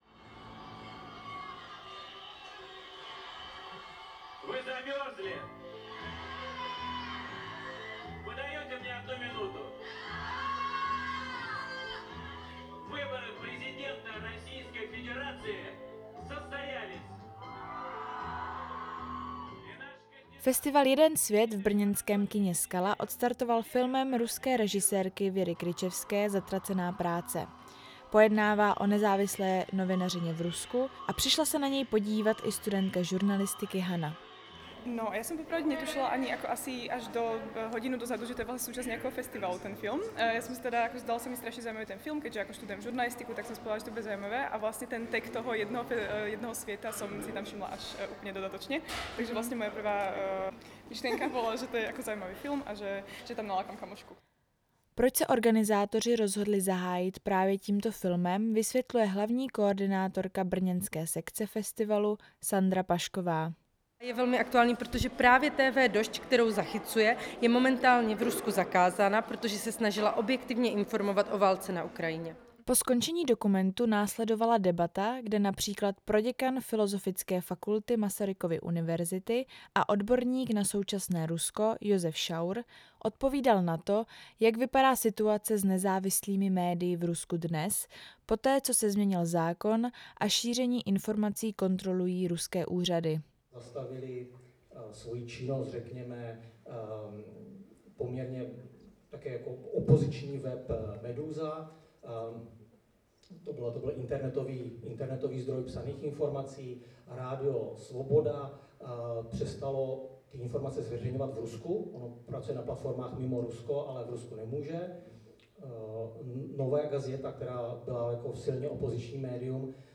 Reportáž: V Brně začal festival dokumentárních filmů Jeden svět